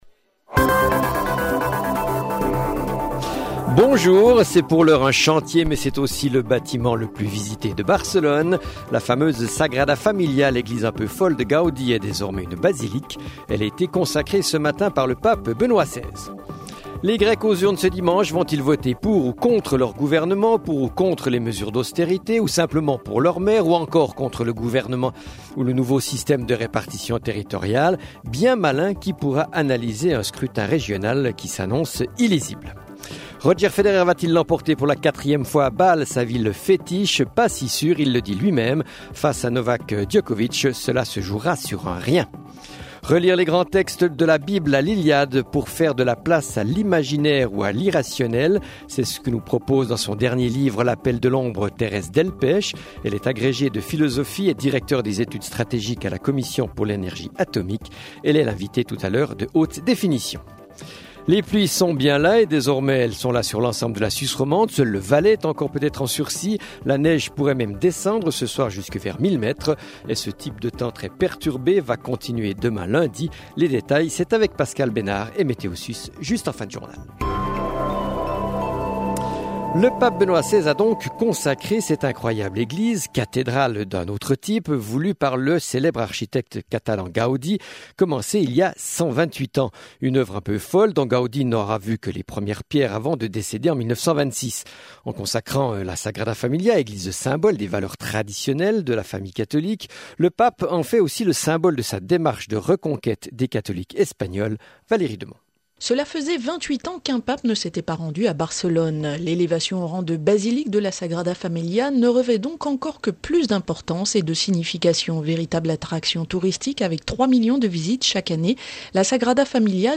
Le 12h30, c’est le grand rendez-vous d’information de la mi-journée. L’actualité dominante y est traitée, en privilégiant la forme du reportage/témoignage pour illustrer les sujets forts du moment.